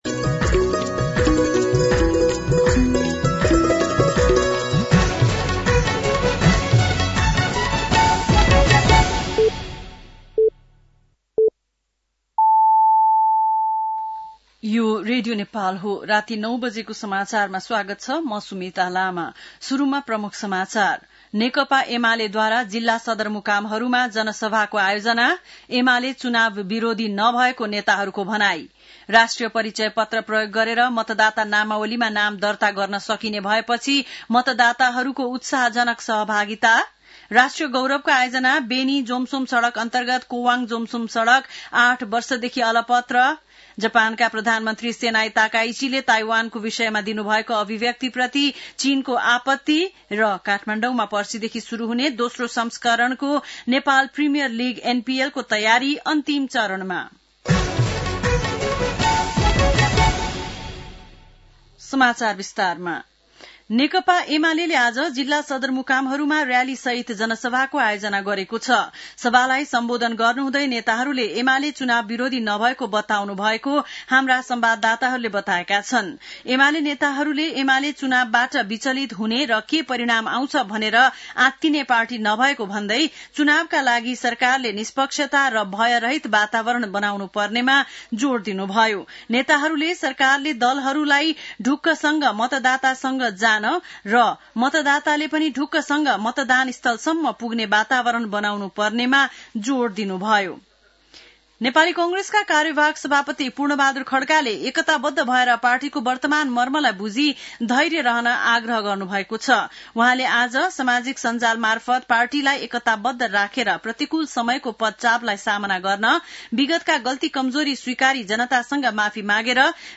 बेलुकी ९ बजेको नेपाली समाचार : २९ कार्तिक , २०८२
9pm-nepali-news-.mp3